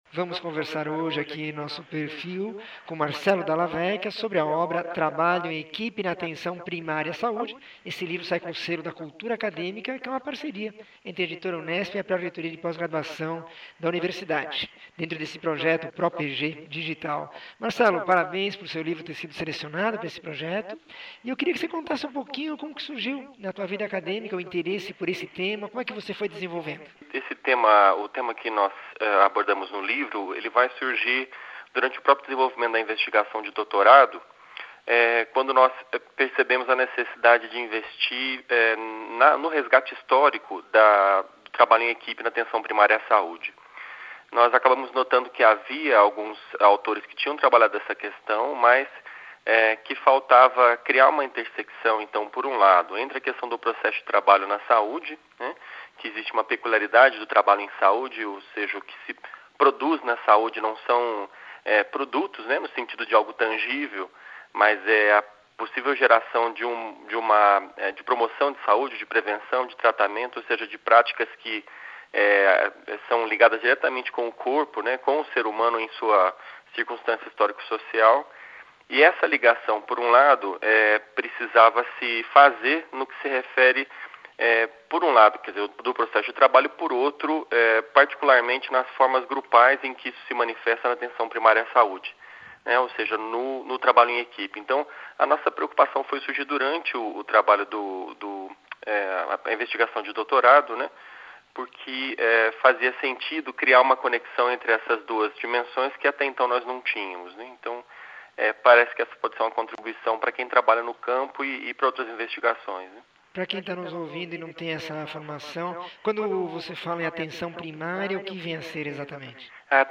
entrevista 1738